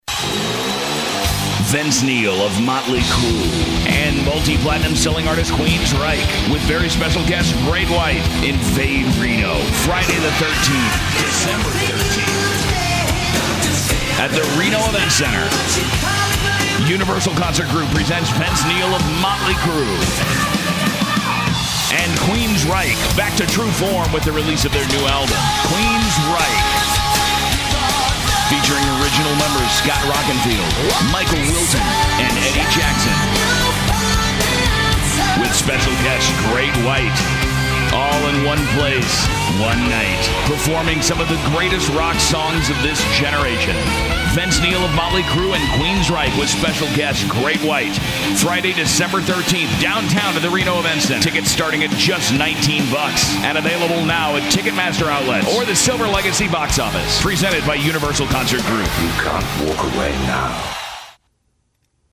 Commercial Production/Voice Over